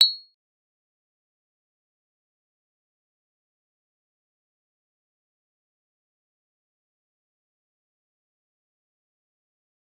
G_Kalimba-B7-f.wav